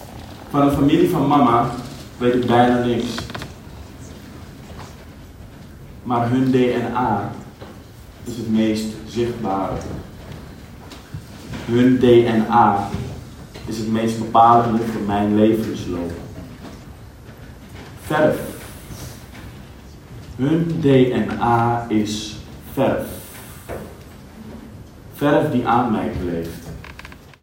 Persoonlijk theater